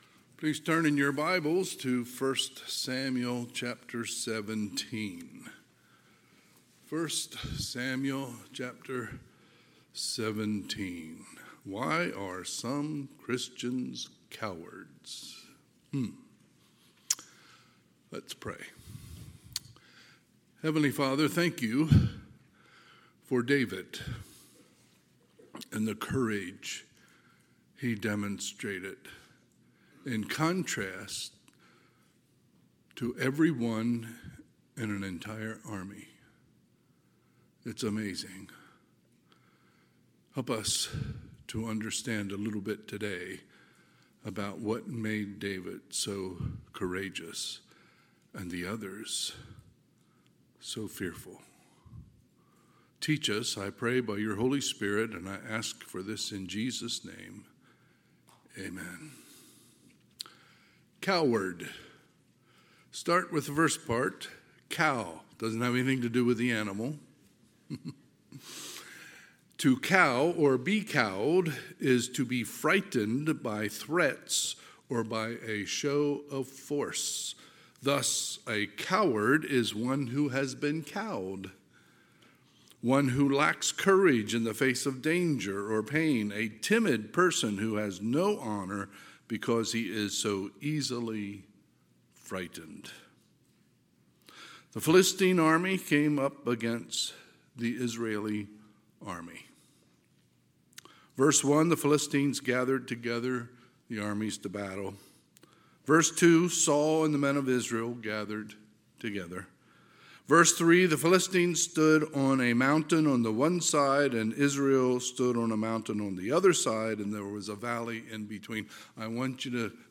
Sunday, February 16, 2025 – Sunday AM
Sermons